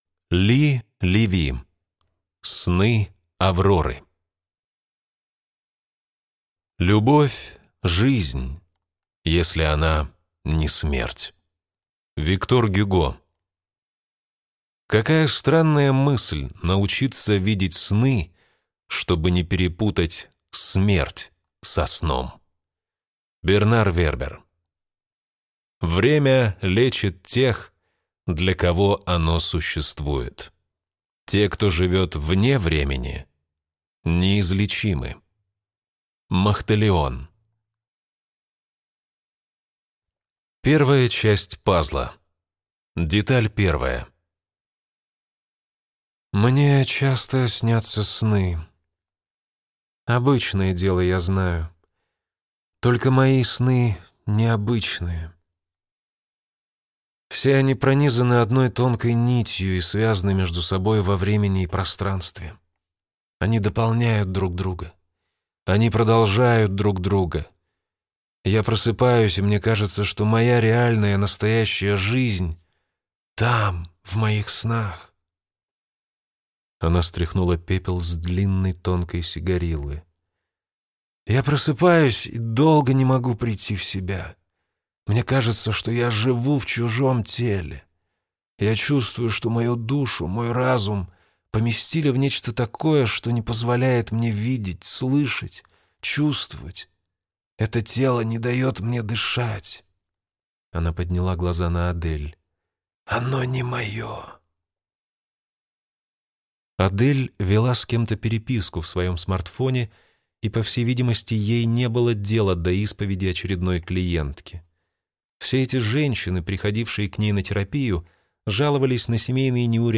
Аудиокнига Сны Авроры | Библиотека аудиокниг